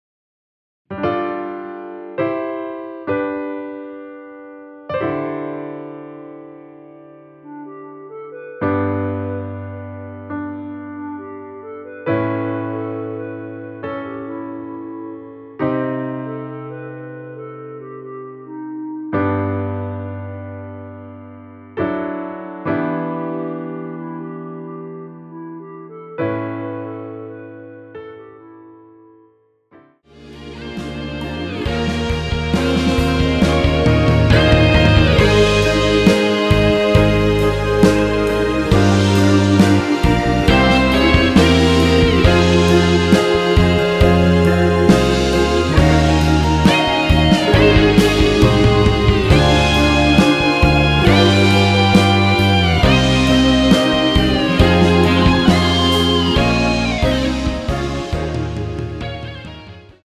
원키에서(-8)내린 멜로디 포함된 MR 입니다.(미리듣기 참조)
노래방에서 노래를 부르실때 노래 부분에 가이드 멜로디가 따라 나와서
앞부분30초, 뒷부분30초씩 편집해서 올려 드리고 있습니다.
중간에 음이 끈어지고 다시 나오는 이유는